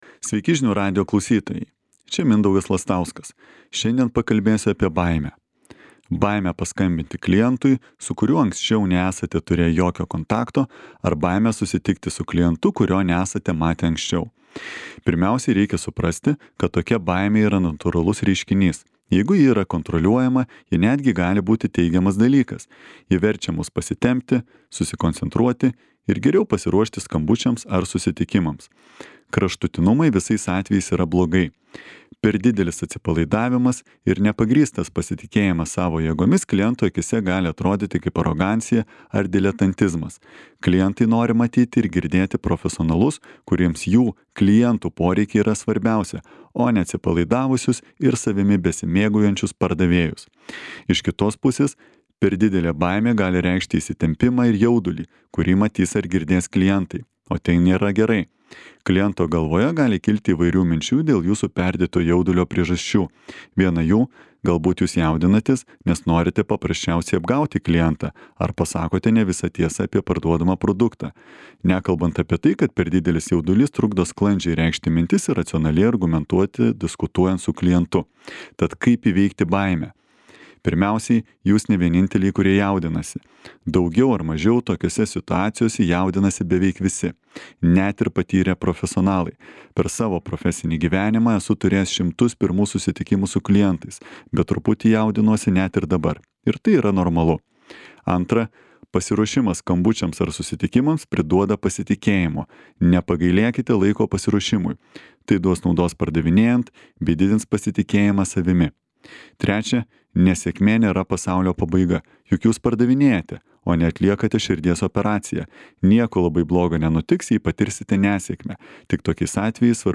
komentaras.